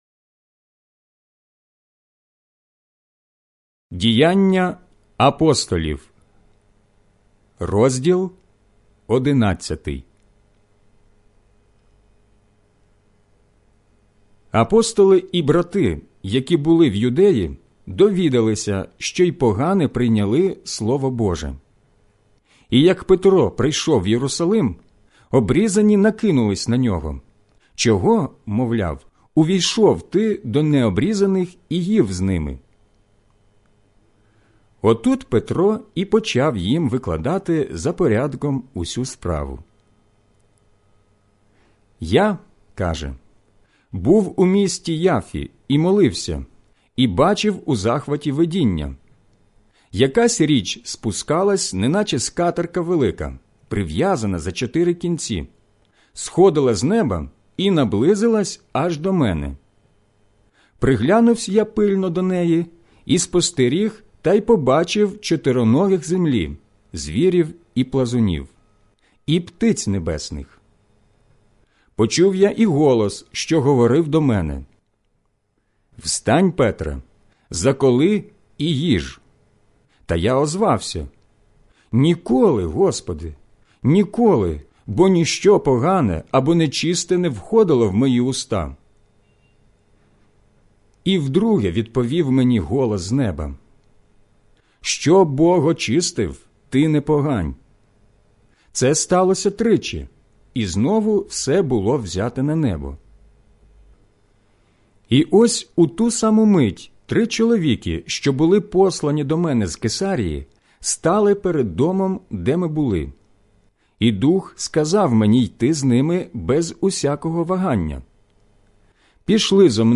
аудіобіблія